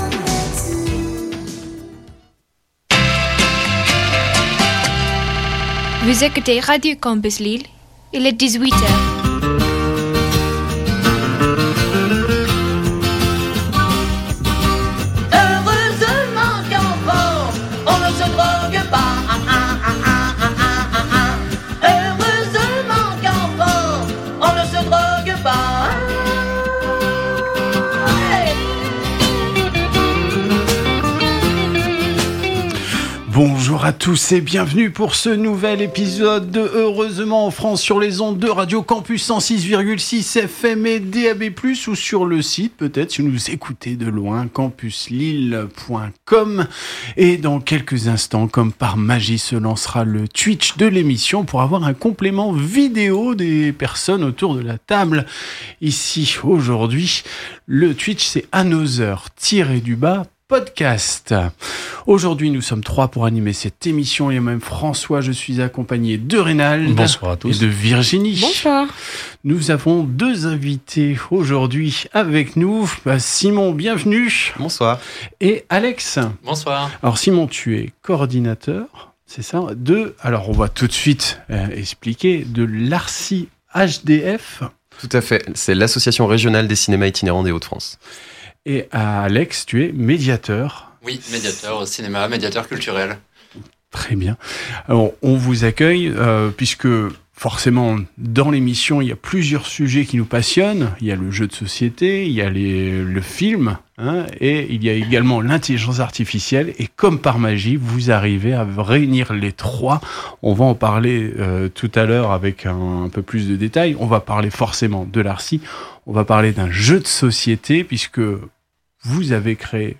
Le 12 Avril 2026, L’ARCI-HdF était invitée à radio campus Lille dans l’émission Heureusement en France. Nous y avons discuté de cinéma itinérant, des ateliers de l’ARCI, de leur développement et plus particulièrement de celui sur l’intelligence artificielle avec le jeu de société ARTFICE !